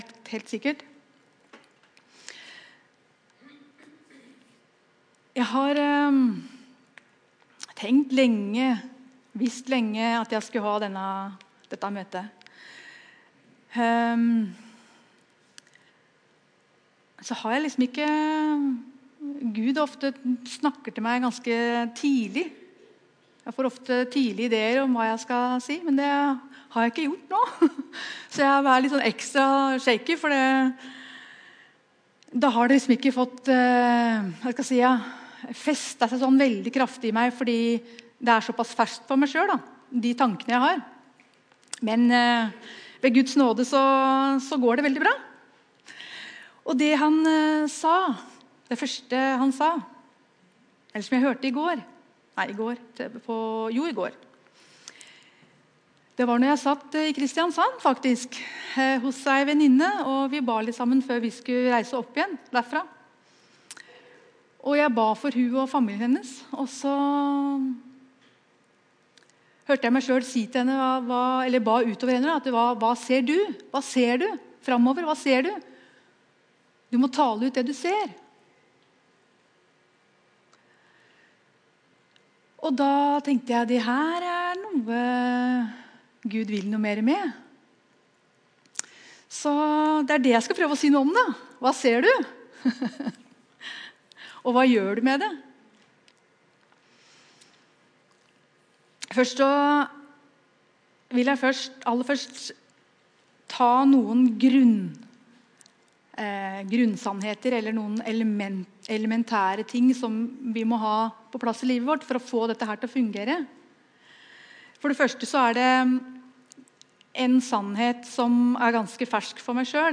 Evangeliehuset Romerike - Gudstjenester Hva ser du?